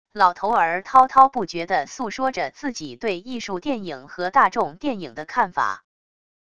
老头儿滔滔不绝地诉说着自己对艺术电影和大众电影的看法wav音频